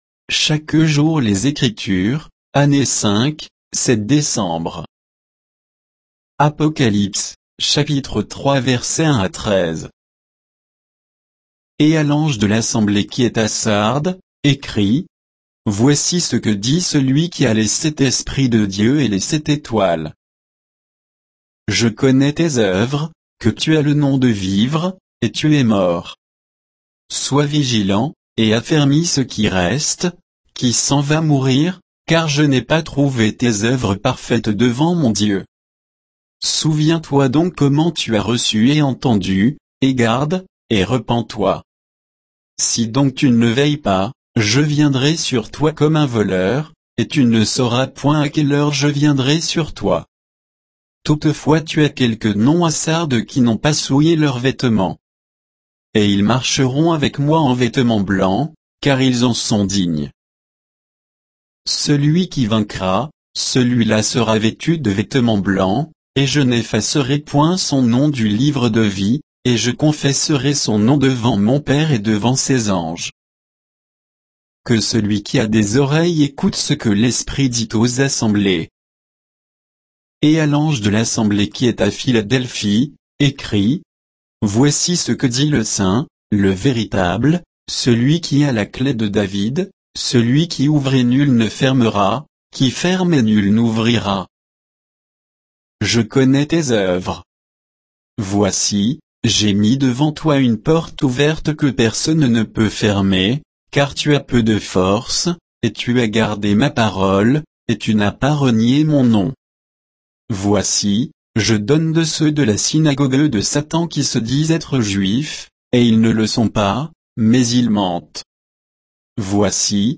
Méditation quoditienne de Chaque jour les Écritures sur Apocalypse 3, 1 à 13